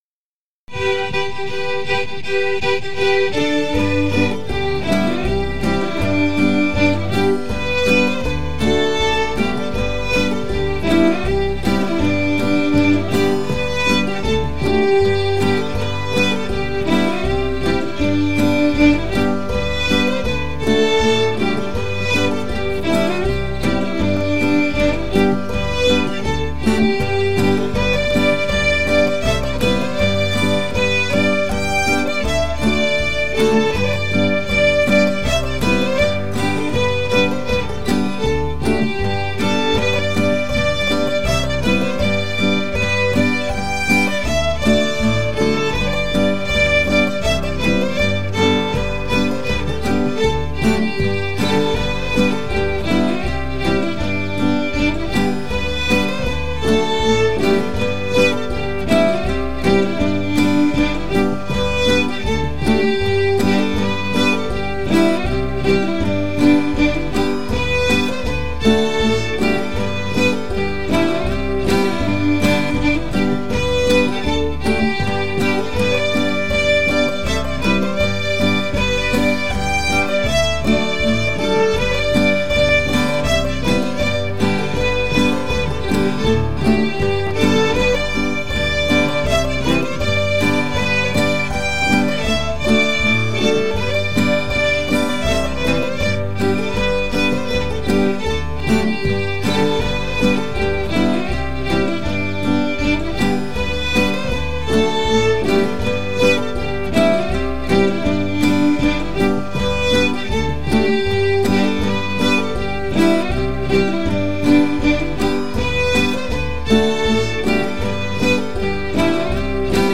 These are open rehearsals and all acoustic string players are welcome.